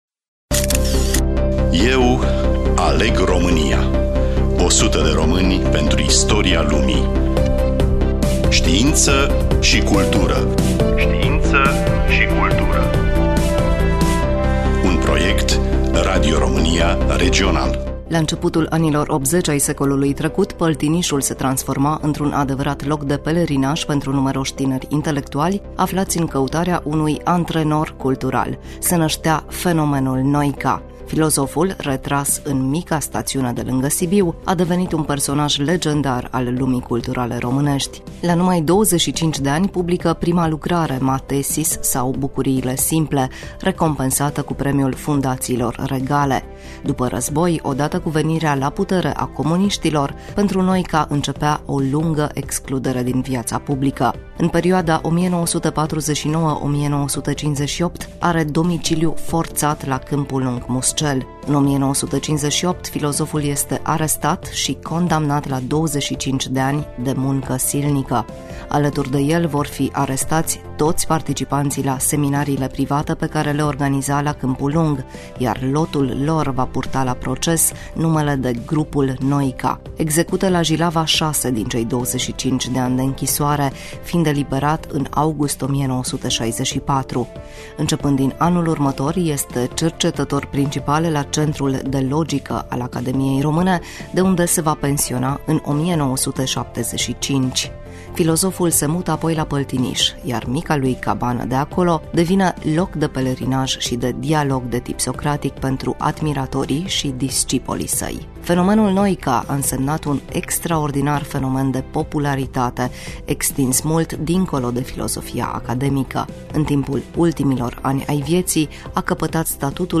Voice-over
Prezentator